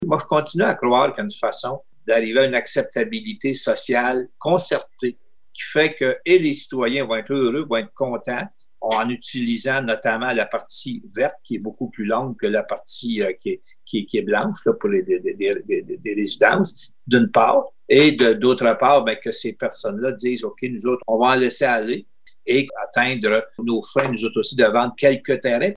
Le maire Jean-Guy Dubois affirme que le dossier est plus complexe qu’il en a l’air. Une partie du terrain est zonée vert, mais l’autre est située en zone blanche, ce qui permet aux propriétaires d’y construire des maisons. Il dit que la Ville cherche une solution qui conviendra aux parties concernées.